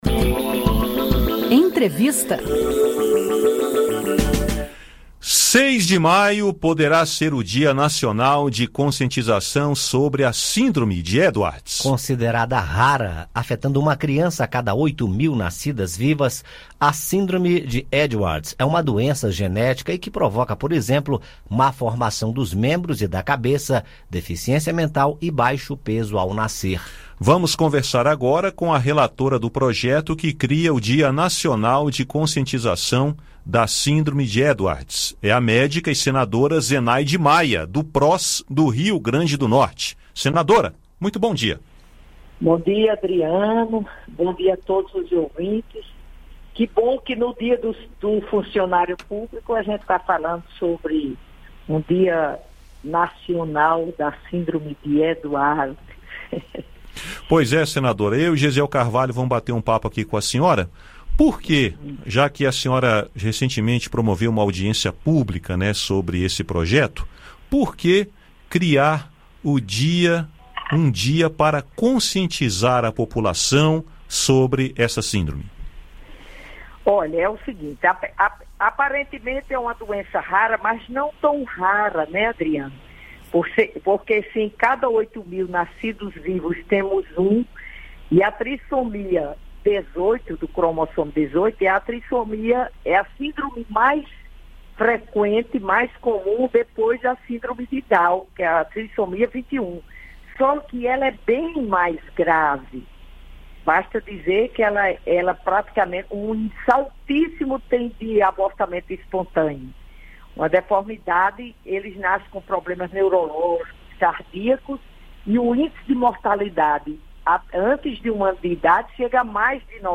A relatora do projeto, senadora Zenaide Maia (Pros-RN), fala sobre a importância de se falar sobre a síndrome.